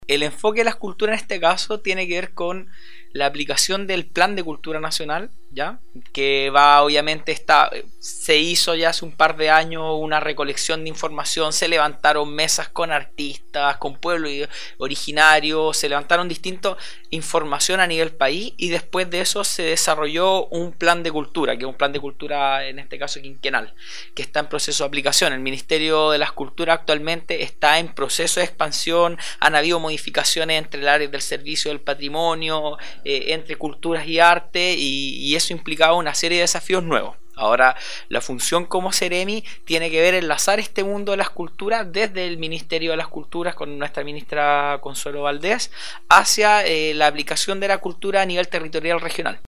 Proyecto “El mundo cívico que todos debemos conocer” entrevista al Seremi de las Culturas y las Artes en La Araucanía